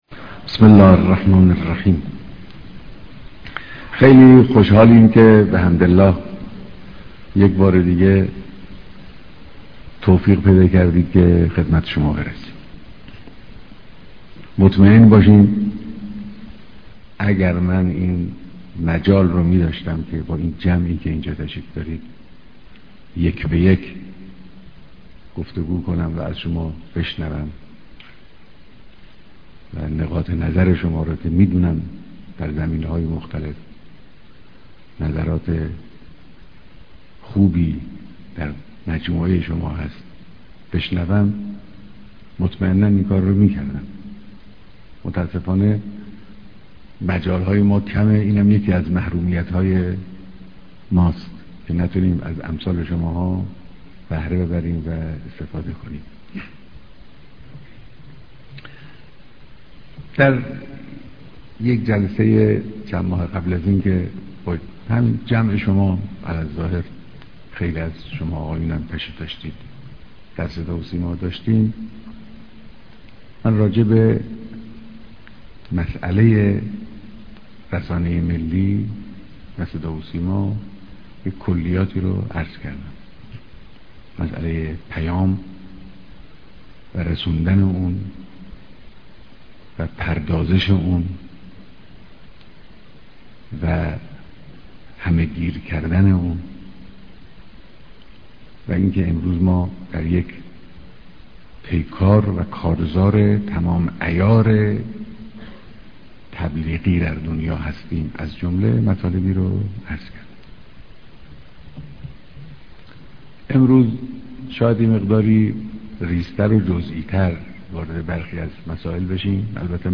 بيانات در ديدار رئيس و مديران سازمان صدا و سيما